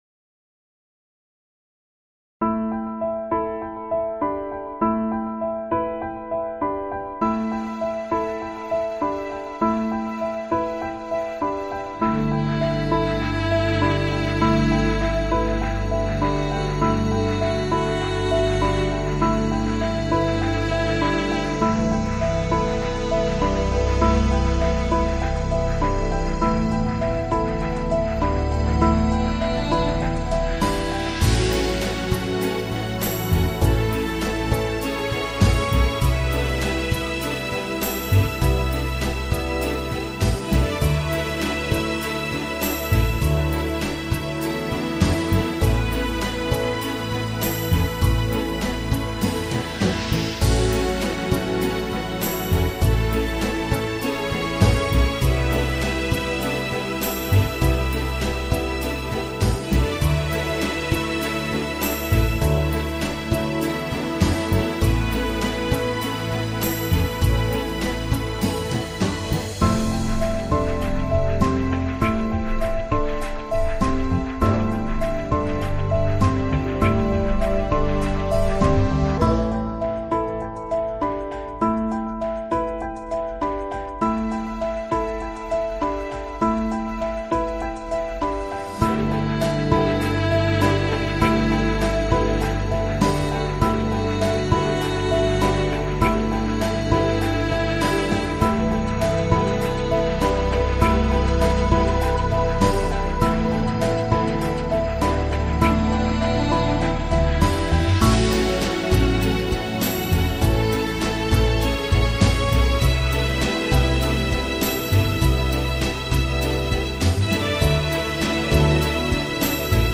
piano - calme - melodieux - melancolique - triste